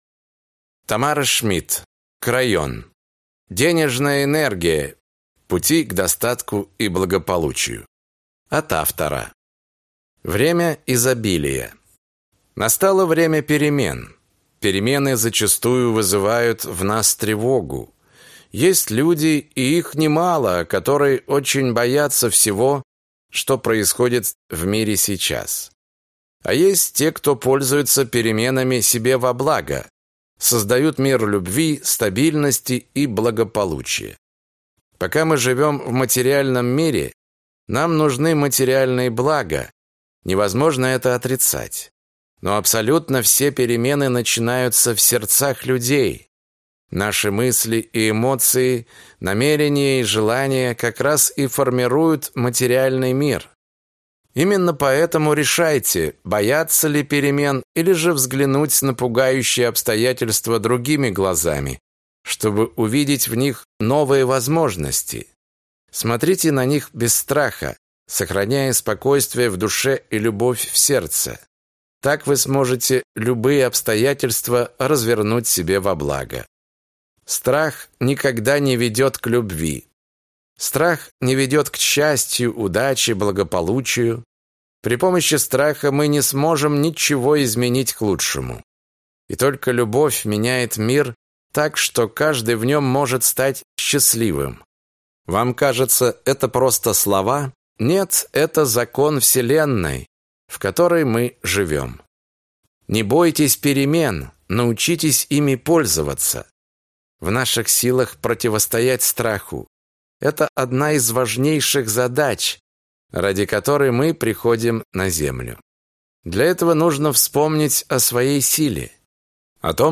Аудиокнига Крайон. Денежная энергия. Пути к достатку и благополучию | Библиотека аудиокниг